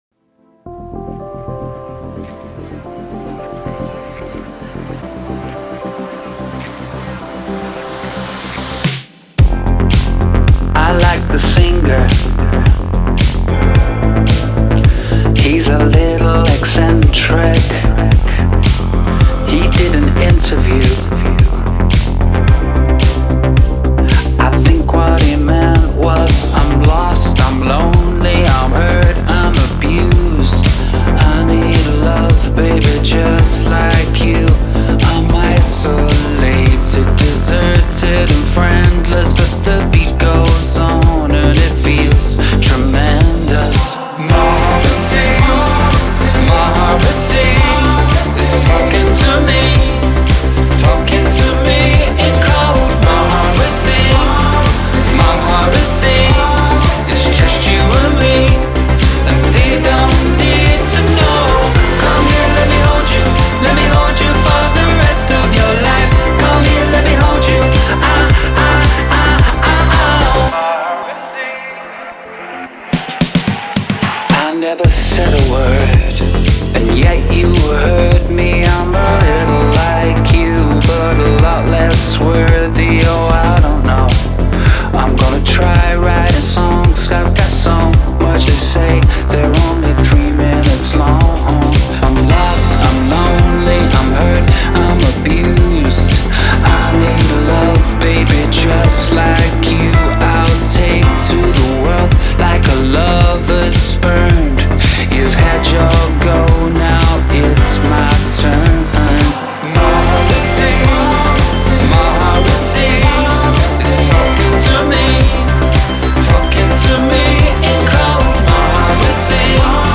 The result is sharp, self aware, and hard to ignore.